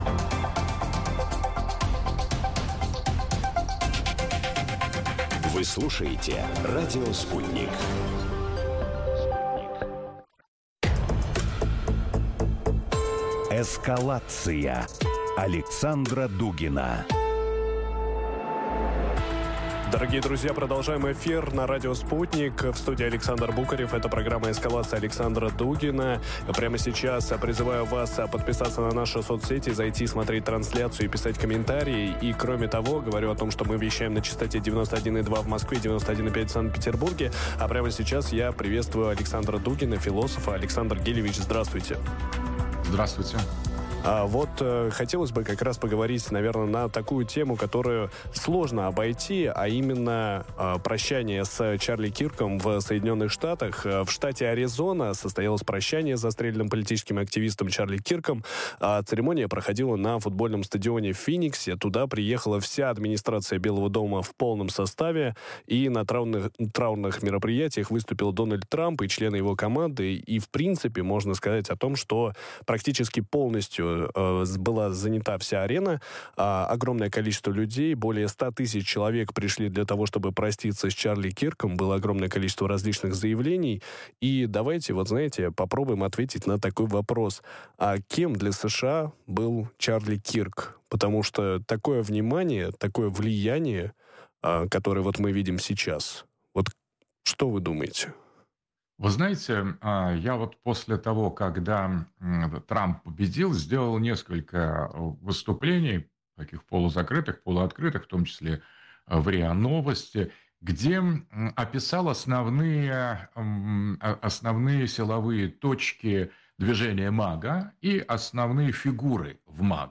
Ответы ищем в эфире радио Sputnik вместе с философом Александром Дугиным.